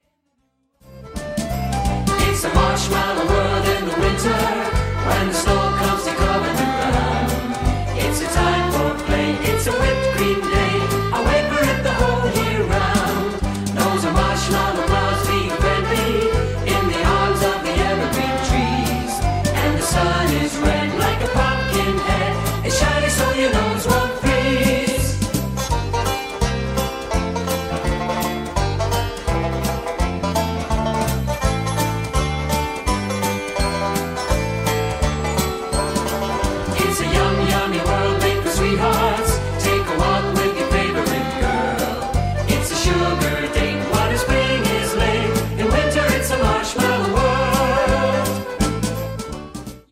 8-beat intro.